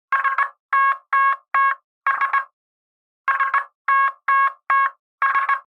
Sos Morse Code
Sos Morse Code is a free sfx sound effect available for download in MP3 format.
SoS Morse Code.mp3